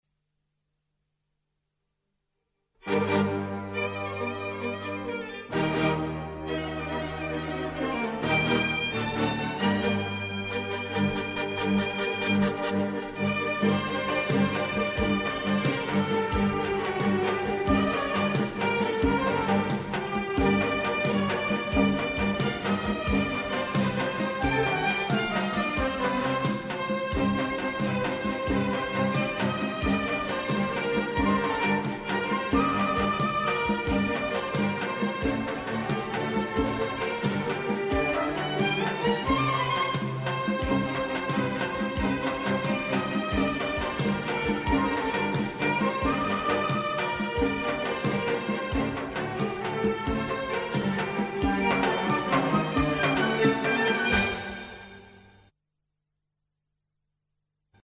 سرودهای شهدا
بی‌کلام